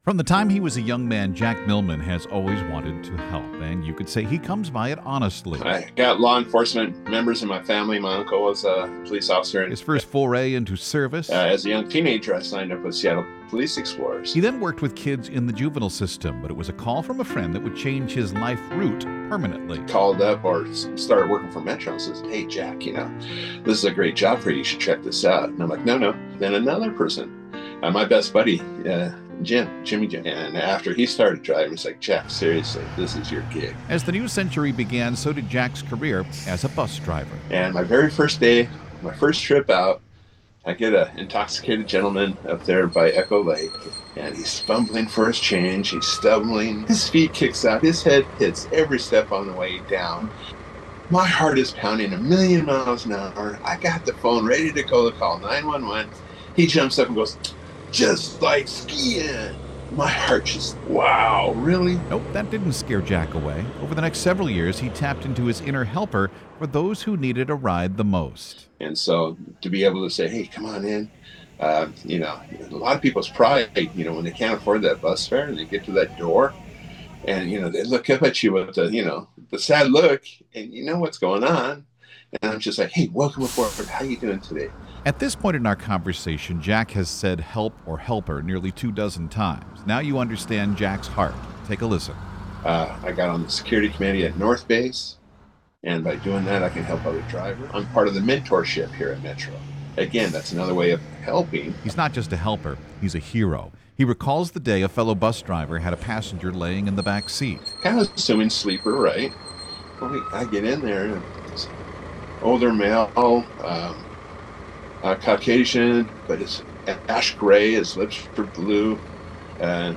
super-driver-bc-feature.mp3